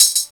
18 HAT 2.wav